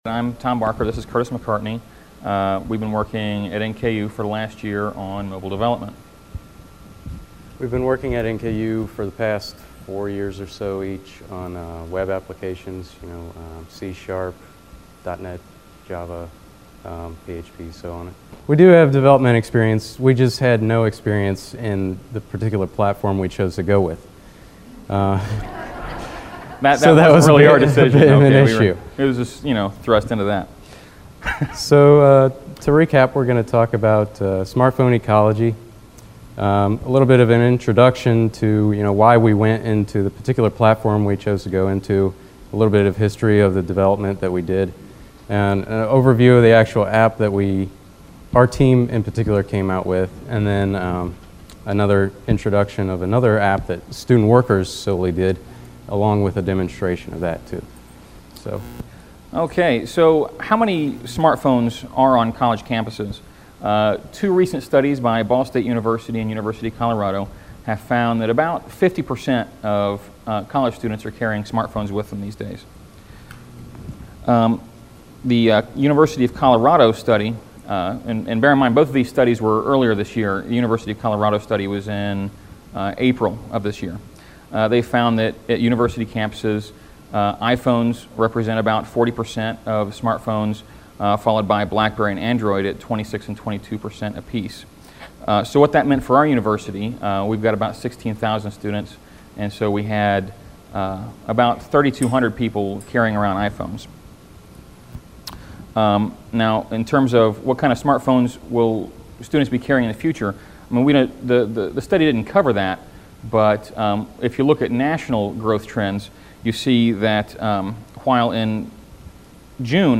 Applications and Standards Track Rookwood, 4th Floor Tuesday, 8:30 – 9:15 AM